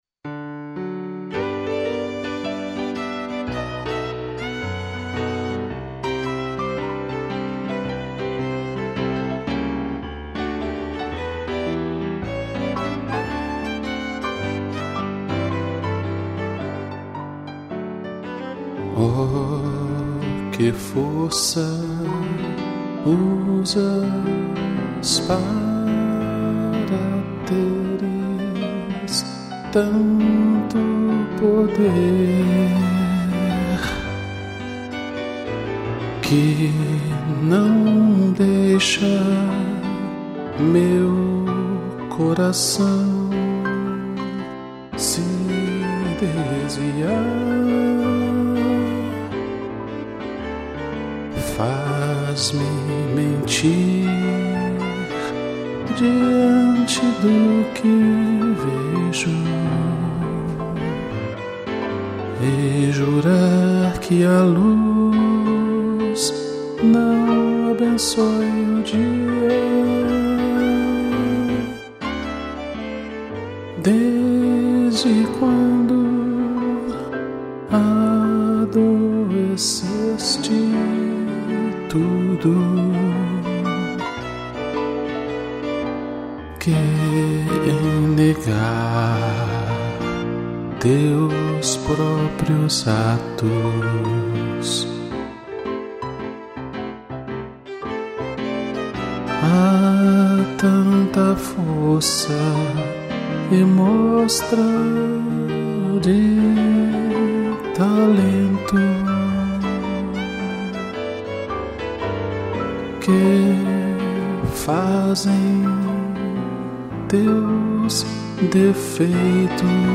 2 pianos e violino